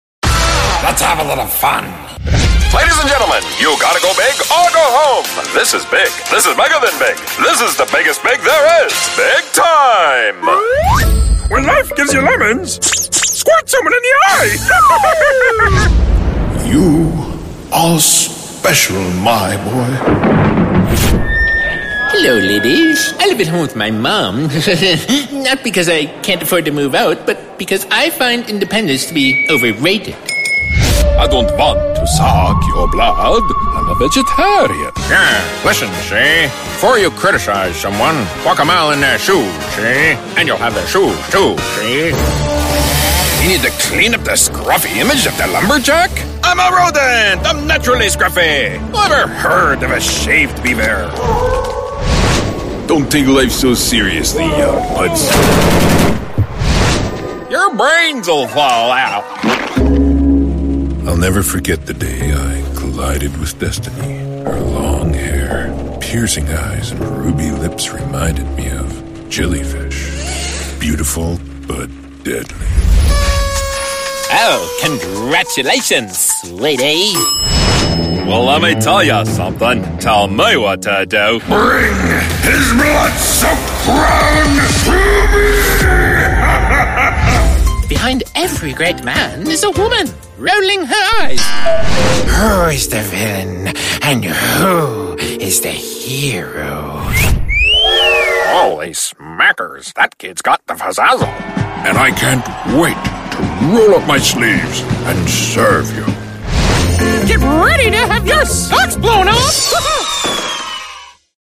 a resonant, warm baritone voice with a neutral Canadian accent
Animation
My dedicated, broadcast-quality studio is Source Connect Certified* and features a Neumann TLM103 microphone, a Universal Audio Apollo X preamp, Audio Technical M50x Studio Headset, MacBook Pro running Adobe Audition, and a hard-wired ethernet connection with 1.5G speed.